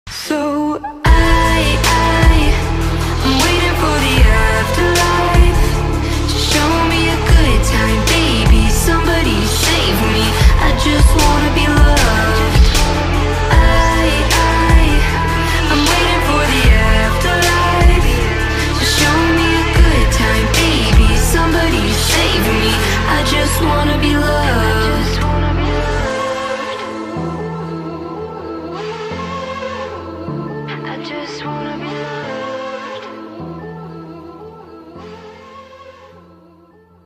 sexy-ish female voice, catchy